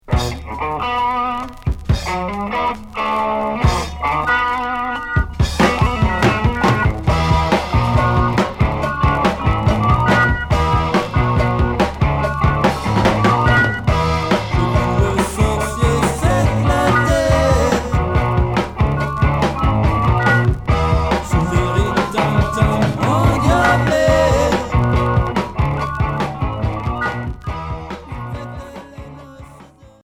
Heavy rock pop